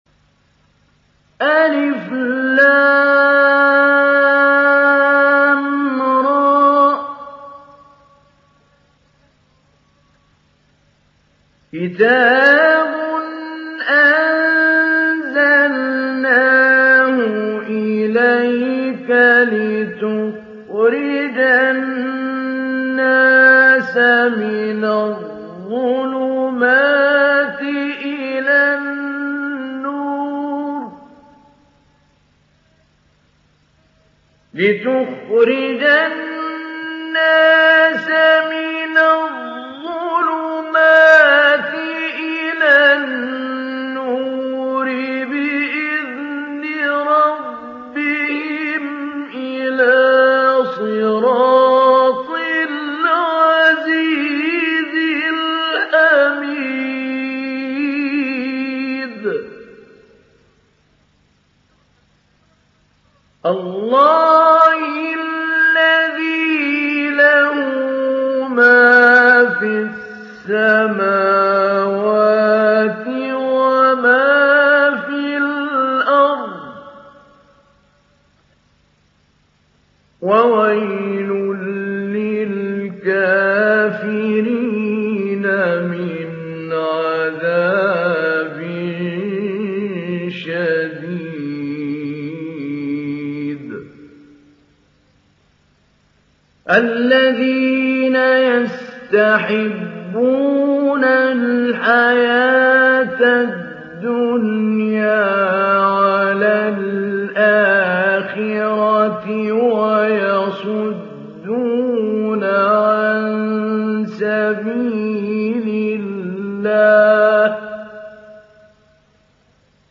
Ibrahim Suresi İndir mp3 Mahmoud Ali Albanna Mujawwad Riwayat Hafs an Asim, Kurani indirin ve mp3 tam doğrudan bağlantılar dinle
İndir Ibrahim Suresi Mahmoud Ali Albanna Mujawwad